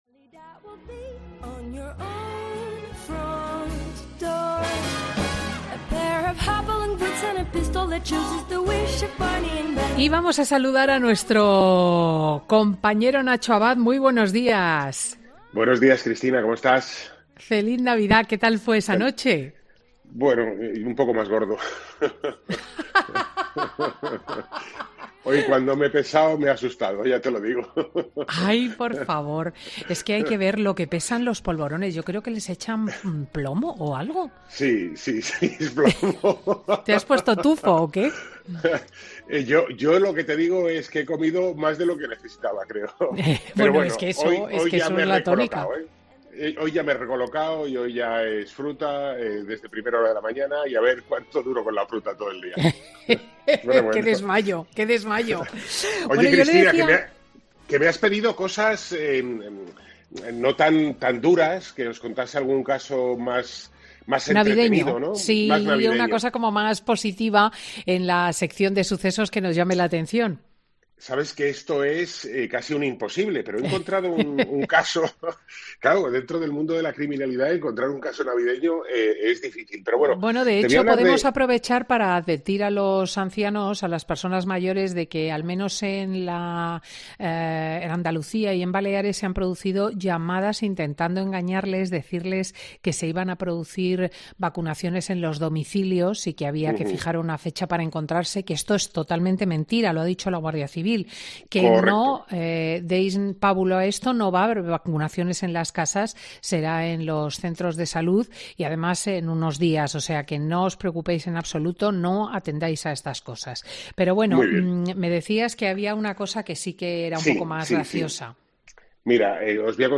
El periodista especializado en sucesos cuenta en Fin de Semana con Cristina todos los detalles del juicio a la exregidora que decidió eximir de las...